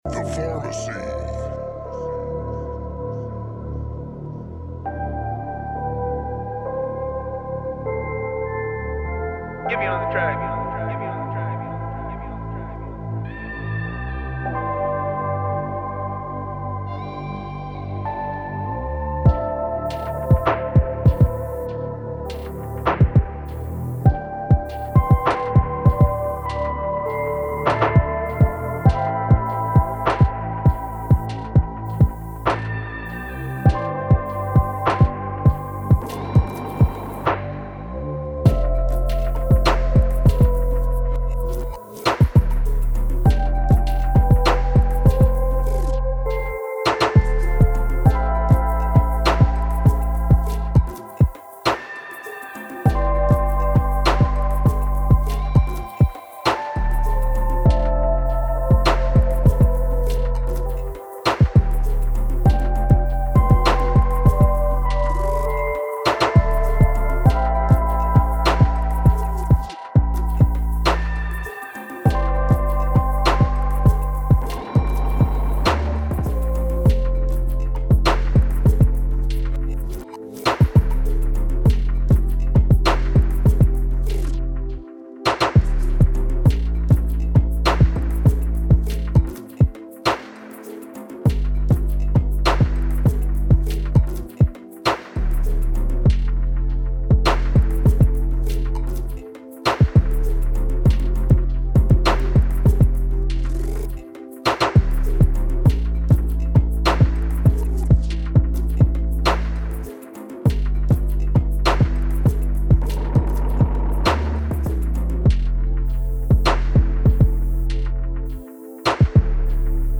Hip Hop , Pop , R&B , Radio Hits , Rap , Trap